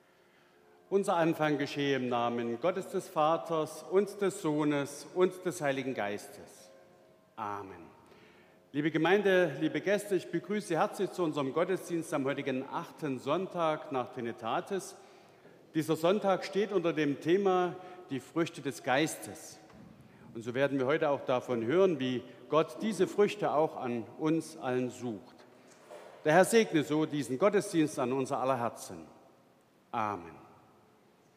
Begrüßung
Audiomitschnitt unseres Gottesdienstes am 8. Sonntag nach Trinitatis 2023